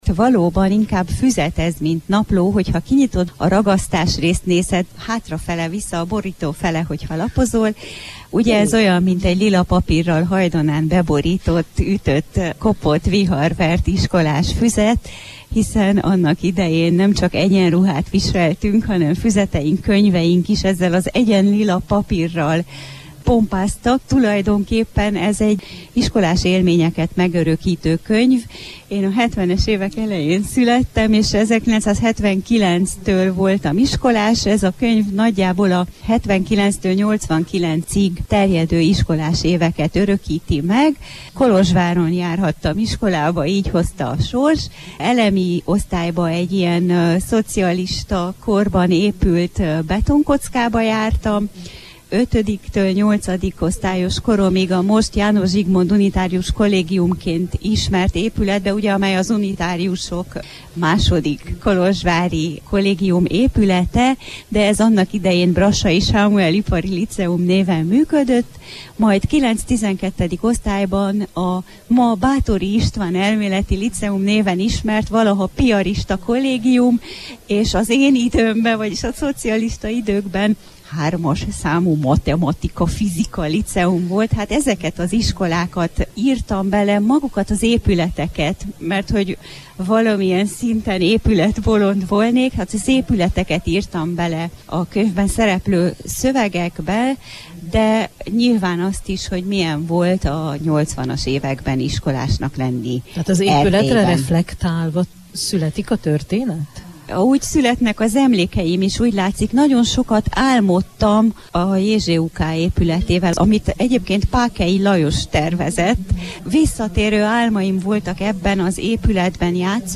Beszélgetés
a Marosvásárhelyi Nemzetközi Könyvvásáron beszélgetett